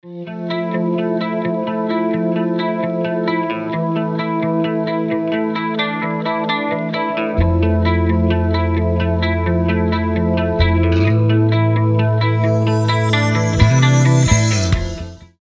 Рингтоны post-grunge
(instrumental) Рок